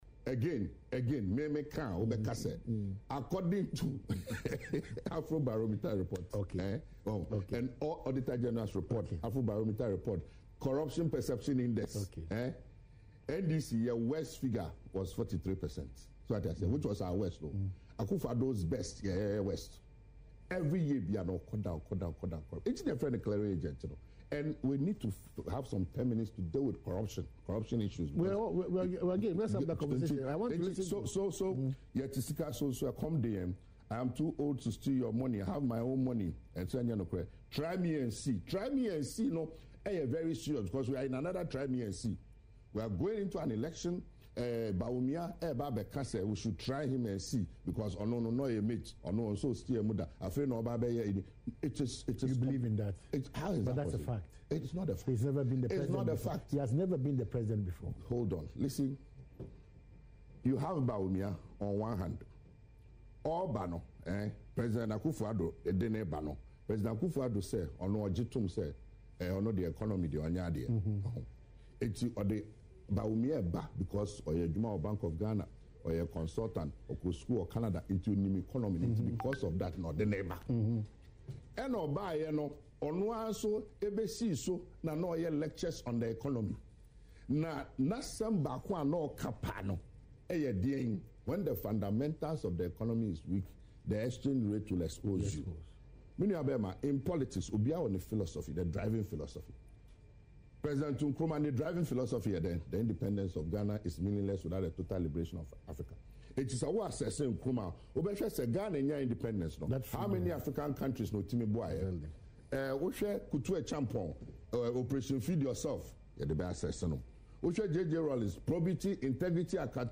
In an interview on Adom TV’s Badwam, the former Minister for Youth and Sports argued that the NDC’s track record is far superior to that of the incumbent government.